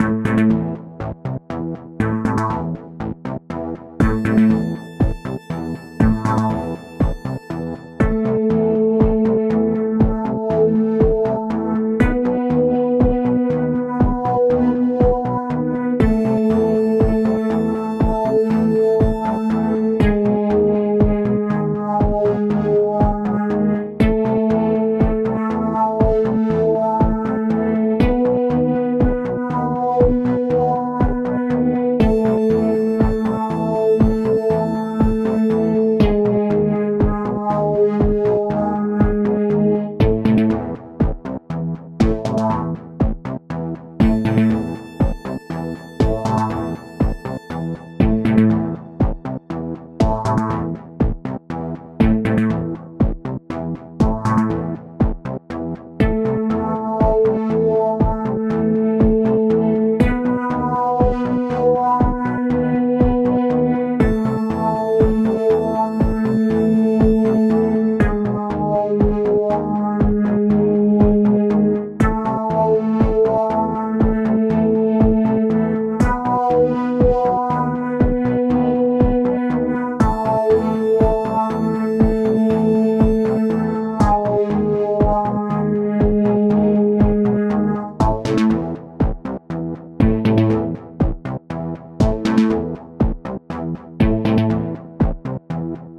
サイバー空間の洞窟にいるようなシーンにぴったりのBGMです！
ループ：◎
BPM：120
キー：Em
ジャンル：みらい
楽器：シンセサイザー